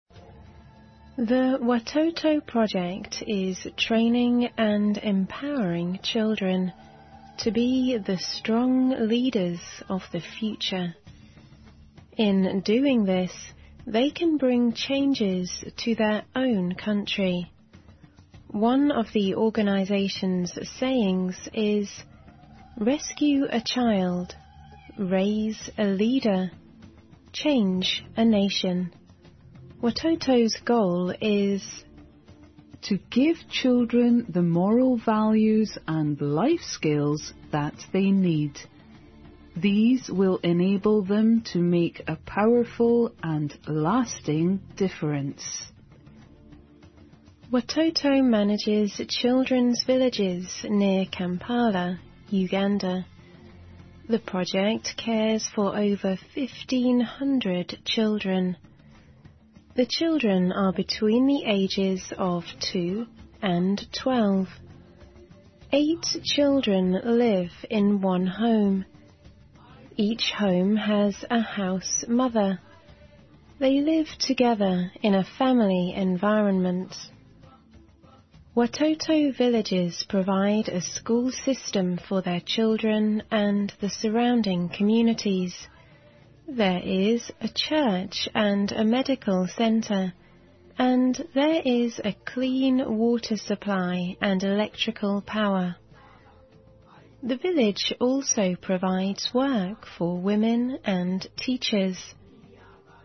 环球慢速英语 第526期:世界艾滋病日—领导力(4)